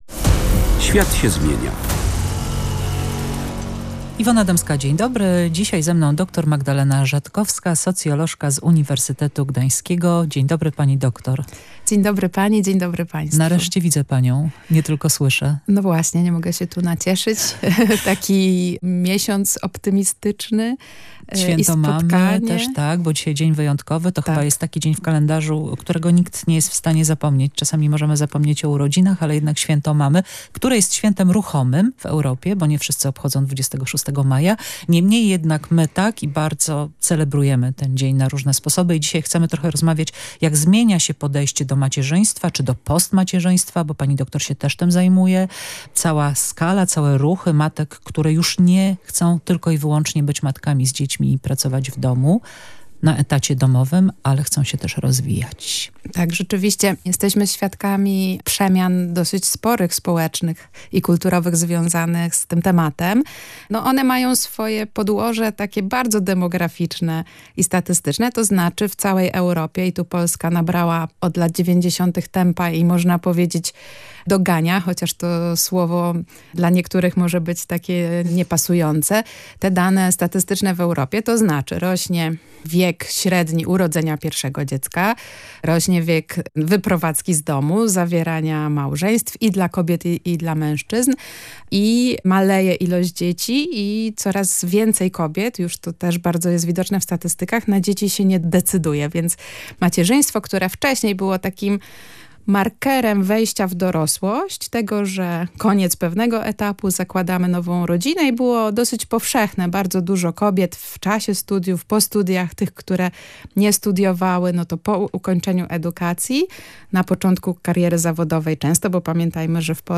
Różne oblicza współczesnego macierzyństwa. Rozmowa z socjolog rodziny